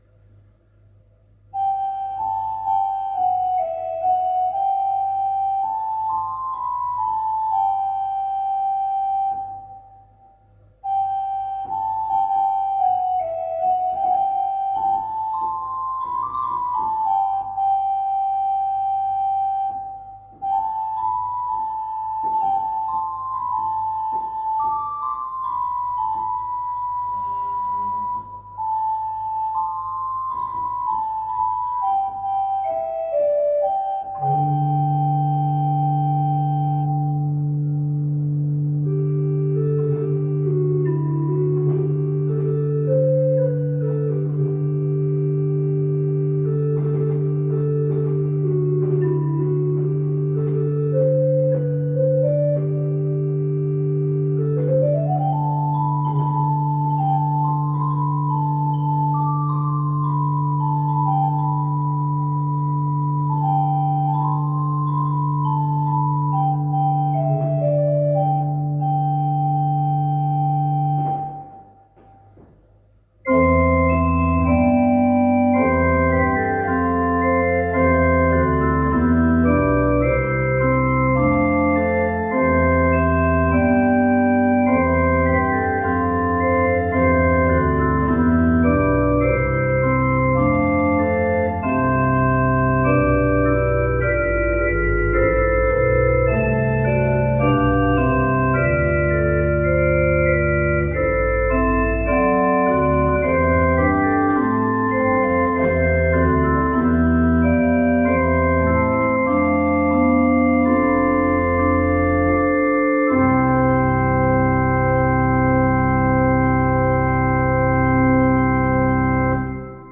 Kirkon nykyiset urut ovat alkuaan suomalaiset, Kangasalan urkutehtaan vuonna 1962 rakentamat ja vuonna 1990 Urkurakentamo Hans Heinrichin Vormsiin pystyttämät ja uudistamat.